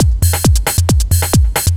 DS 135-BPM A7.wav